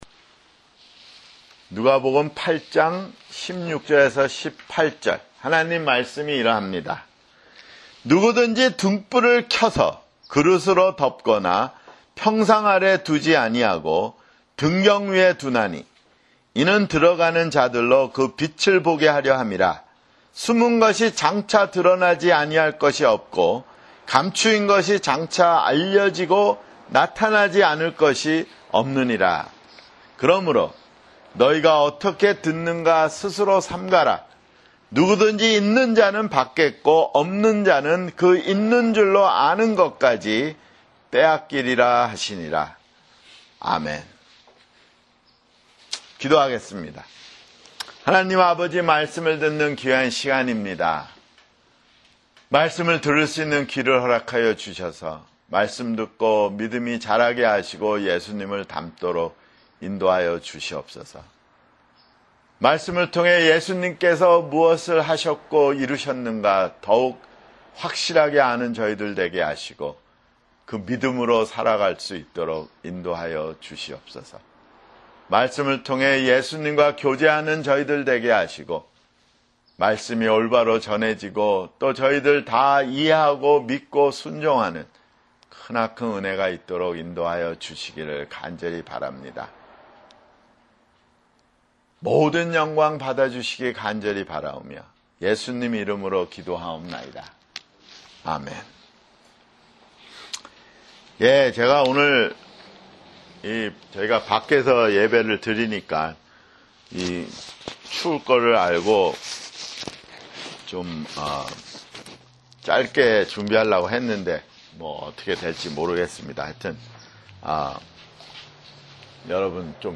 [주일설교] 누가복음 (56)